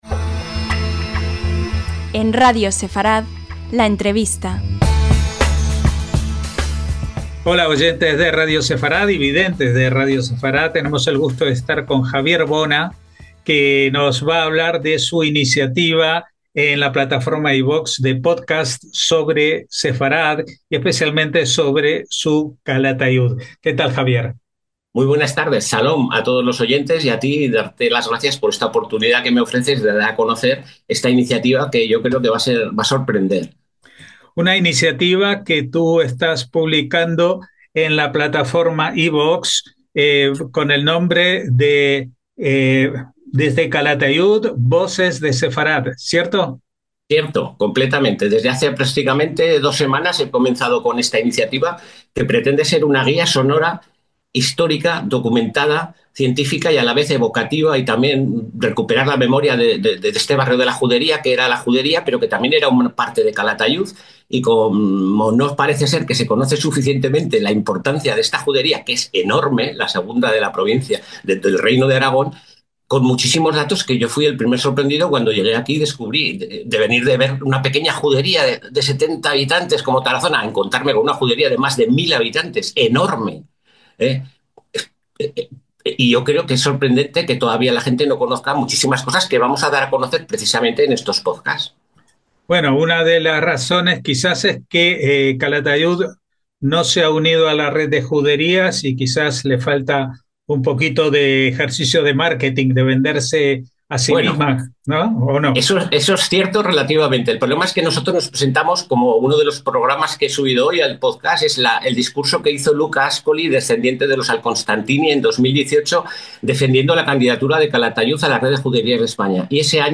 LA ENTREVIOSTA